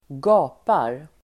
Uttal: [²g'a:par]